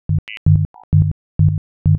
drums_ifft.wav